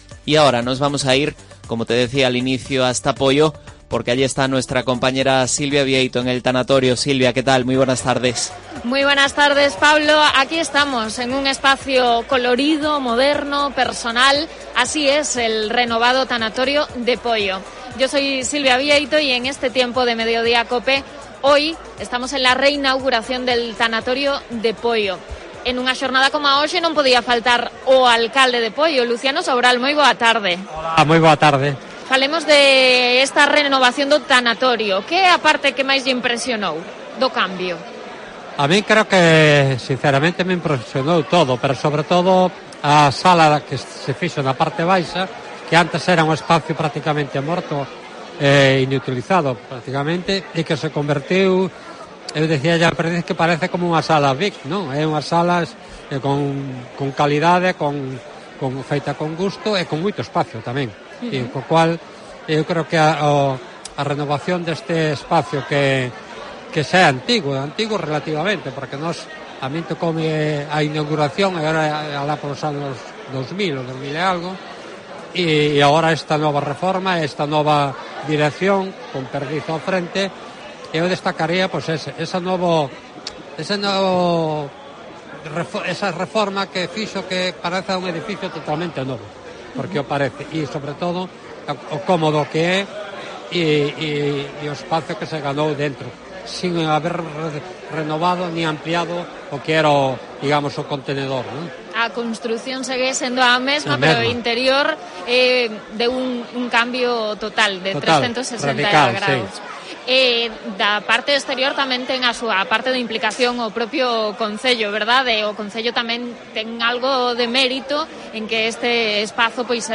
Entrevista al alcalde Luciano Sobral en la presentación del renovado Tanatorio de Poio